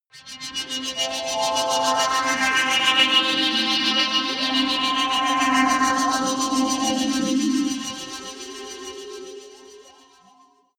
Gemafreie Sounds: Atmosphären